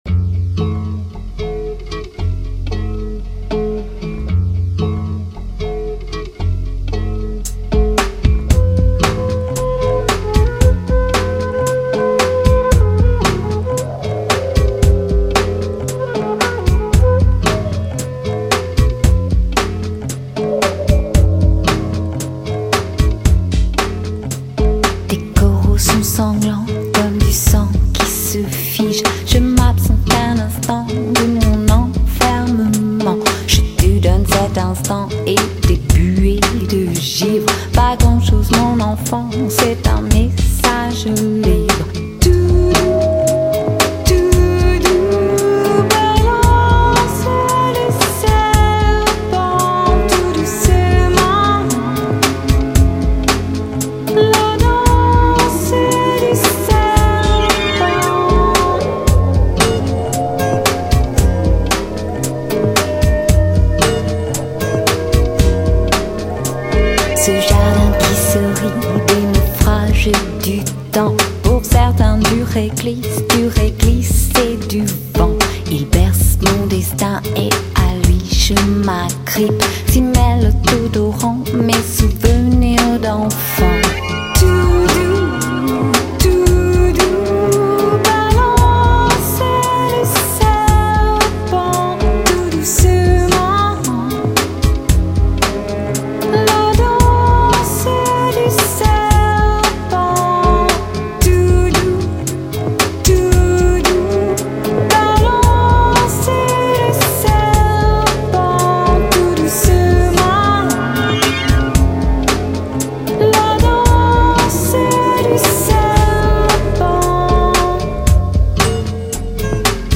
无法排遣让Lounge Music来解放你，放轻松听时尚，呼吸电音，虚幻
缥缈却不落俗套，空灵迷幻却不过分寒冷。
乐类型。舒服、放松、悠闲、自在，唱机里的沙发音乐轻快而惬意，